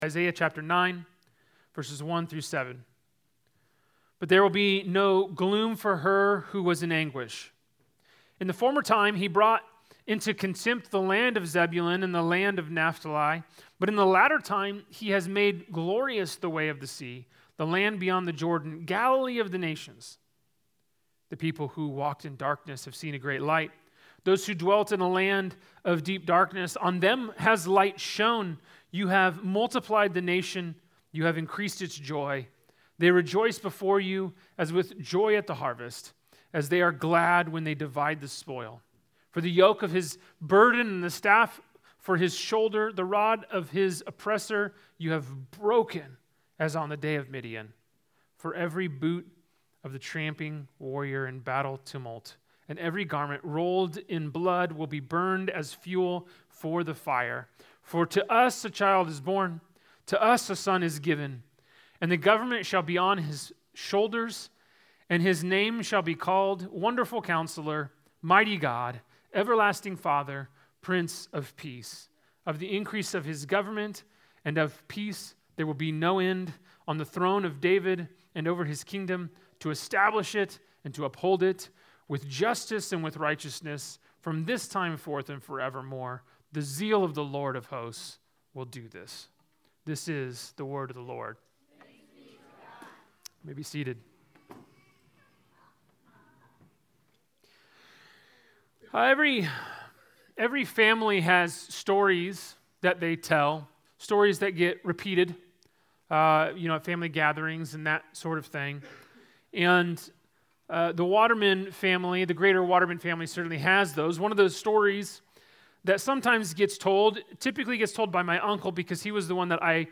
Sermons from Proclaim Church